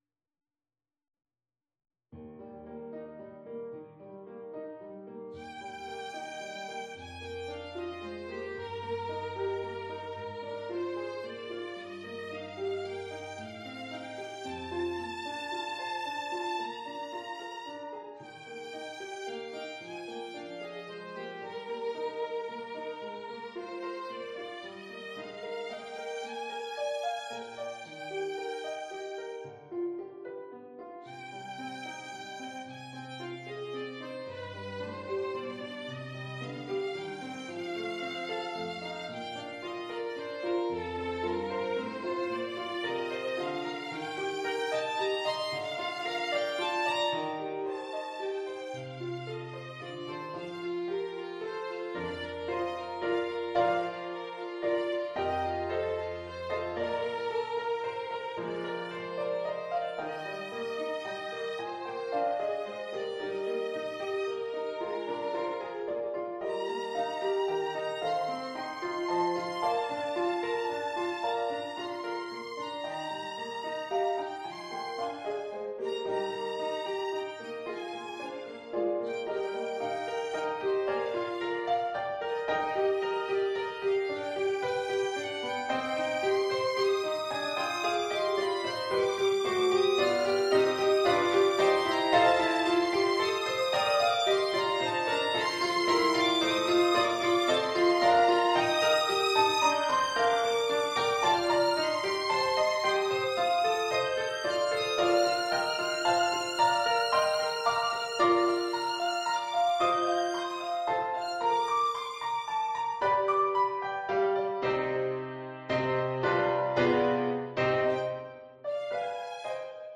Violin Sonata in B-flat major, 2nd movement, Andante molto cantabile